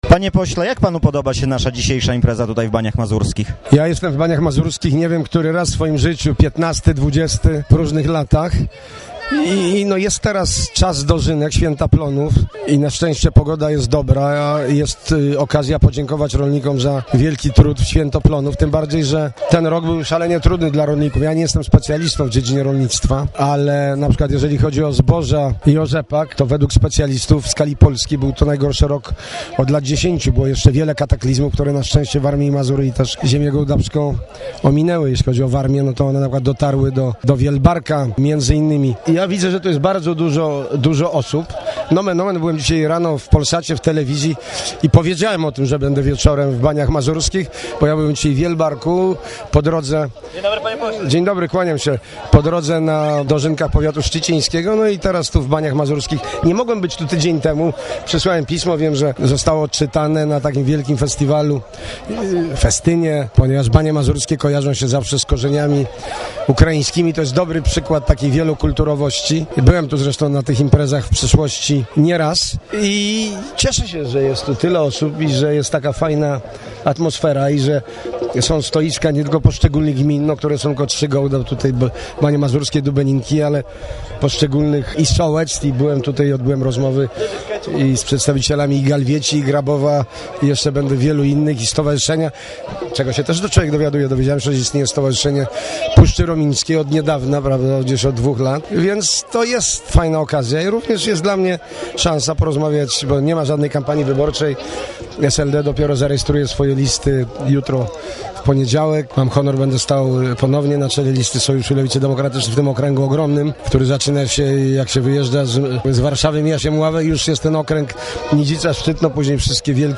mówi Tadeusz Iwiński, poseł Sejmu RP